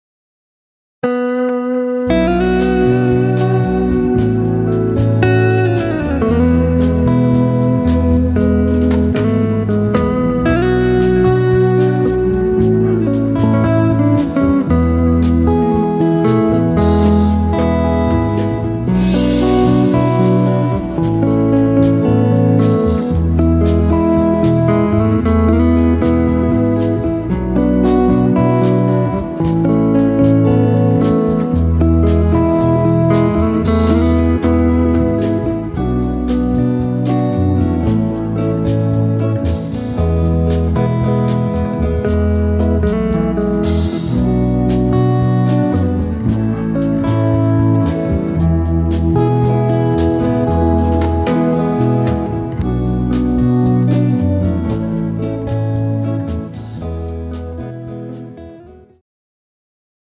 Rock bossa style
tempo 114 bpm
male backing track
This backing track is in soft rock bossanova style.
Male singer version: